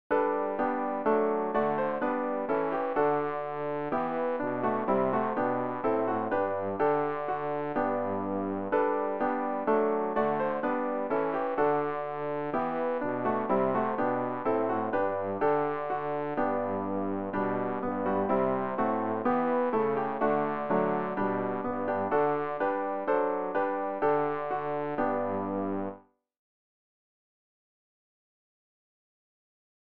bass-rg-344-unsern-ausgang-segne-gott.mp3